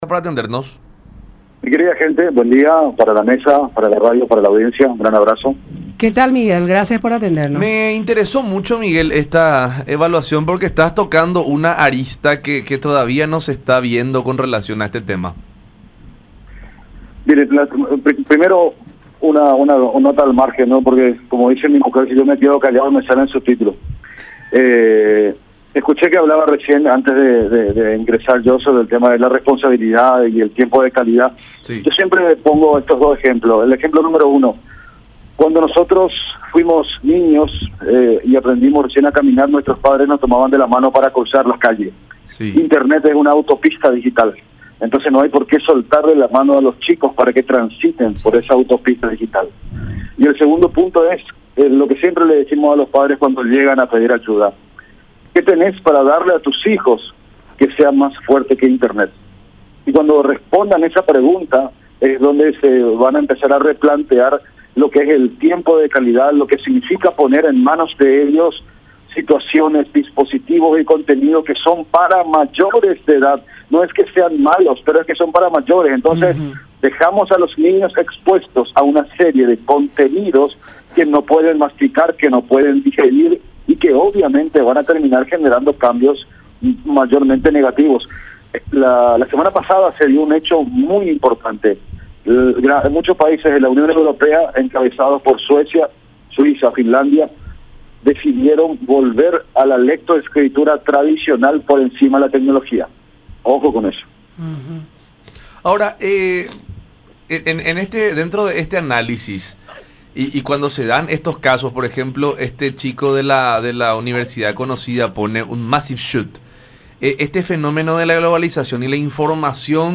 en diálogo con La Mañana De Unión por Unión TV y radio La Unión.